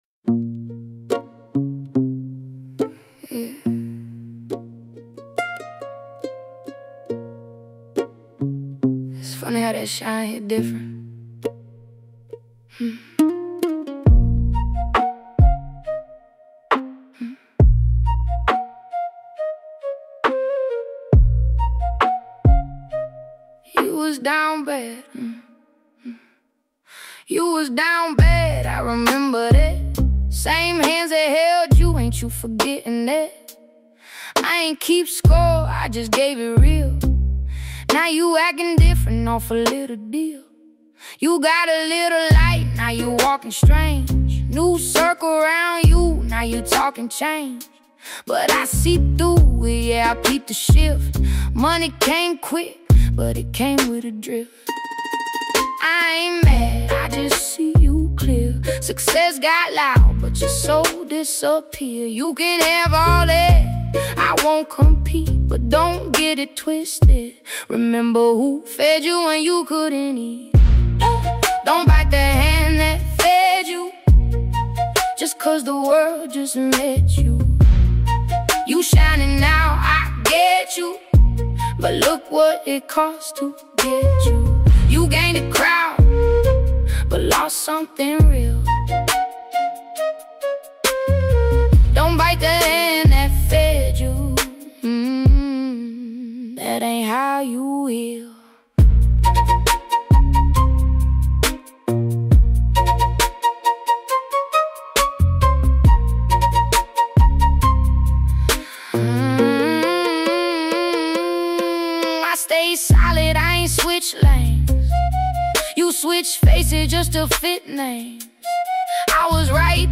Simple, clean, and direct.